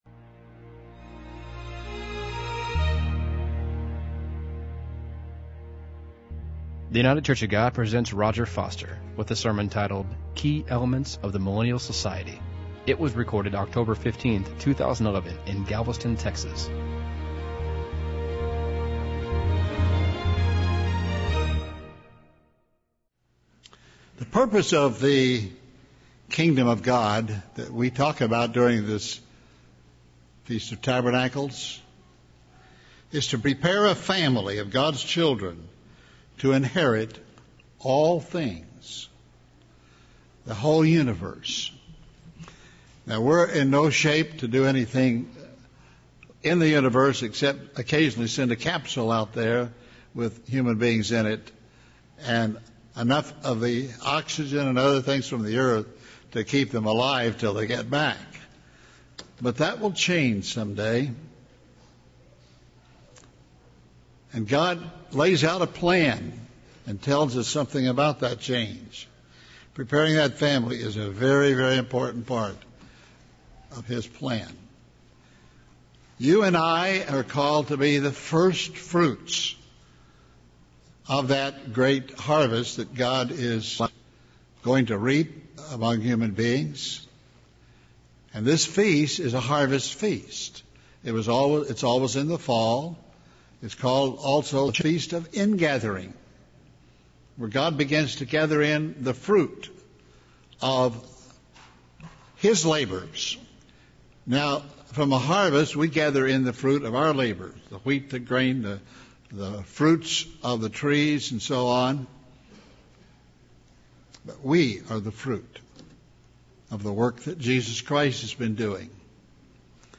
This sermon was given at the Galveston, Texas 2011 Feast site.